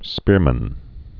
(spîrmən)